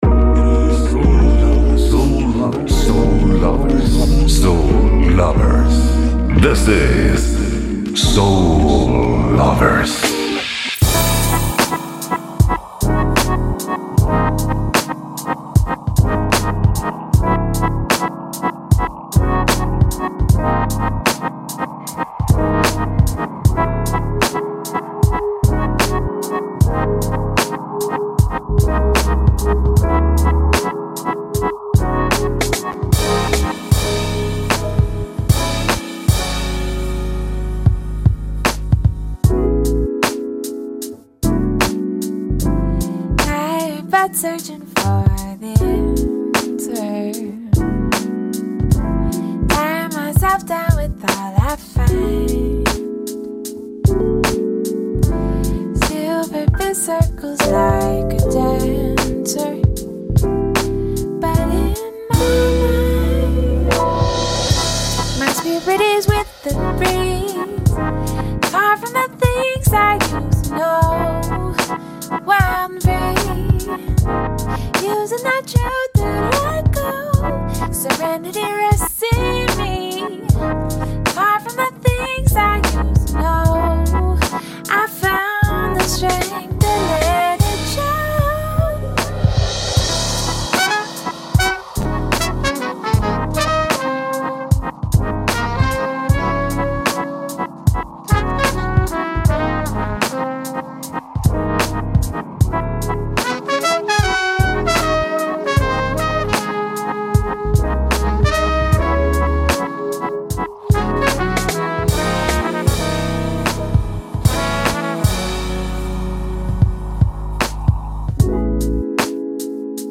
Questa settimana navighiamo tra novità anacronistiche, brani atemporali, la pucciosità dei blue-eyed soulmen dei giorni nostri e di dischi dimenticati che si nascondevano sotto strati di polvere.